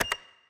sfx_passcode_button_press.wav